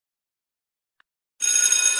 Bike Bell
Bike Bell is a free foley sound effect available for download in MP3 format.
519_bike_bell.mp3